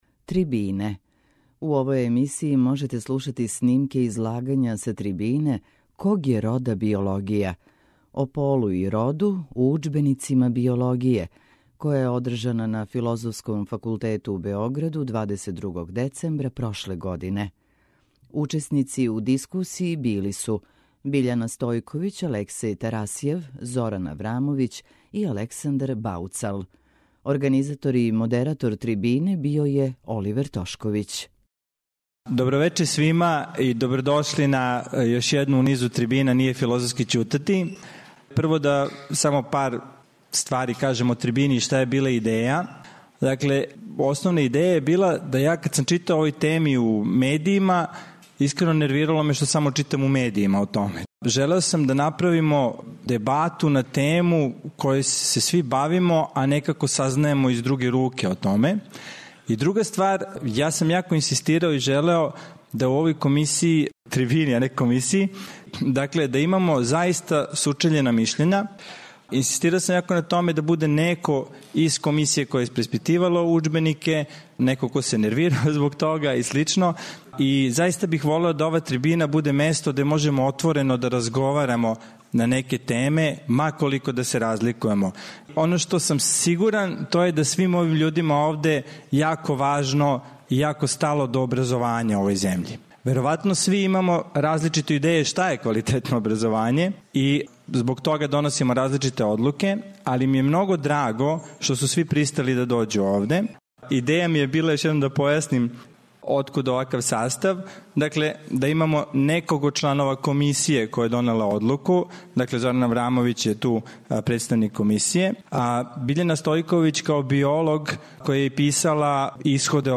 Трибине
Снимак је забележен 22. децембра прошле године на Филозофском факултету Универзитета у Београду.